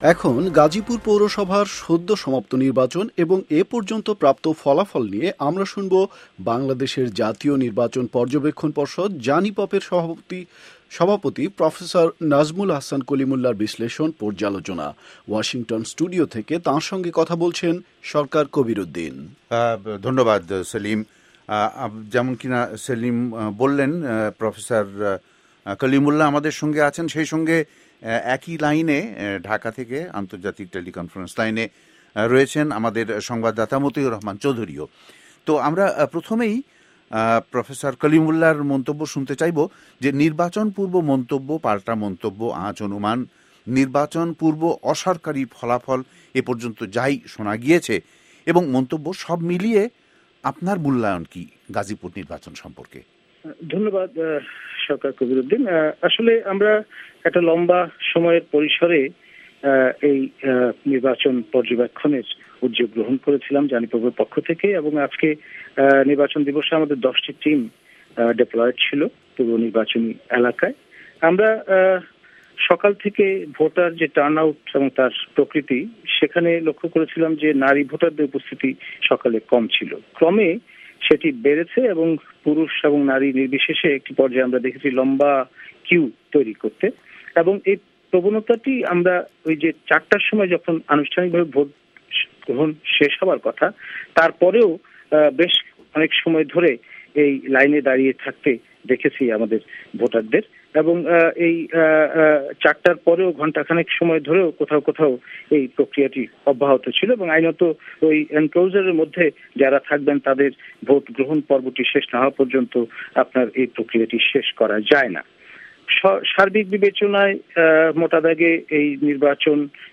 ওয়াশিংটন স্টুডিও থেকে তাঁর সঙ্গে কথা বলছেন